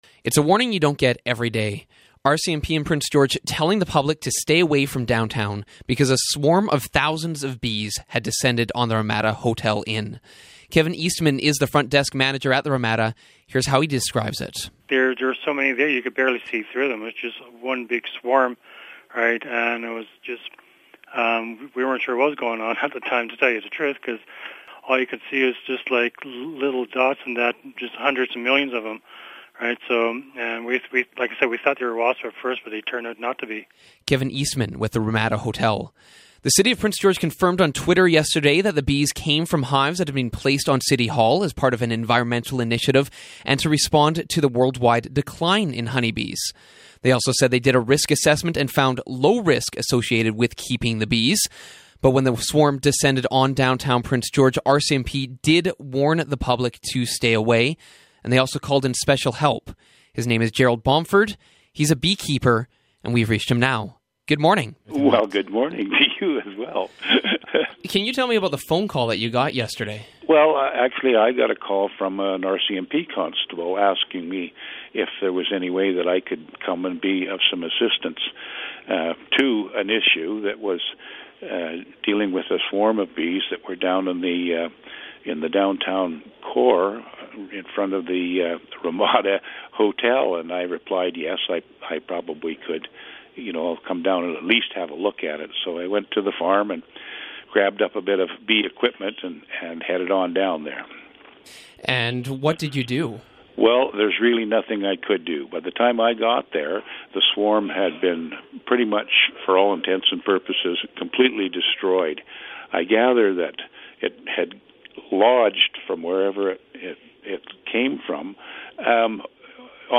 He's a beekeeper, and he tells us what happened.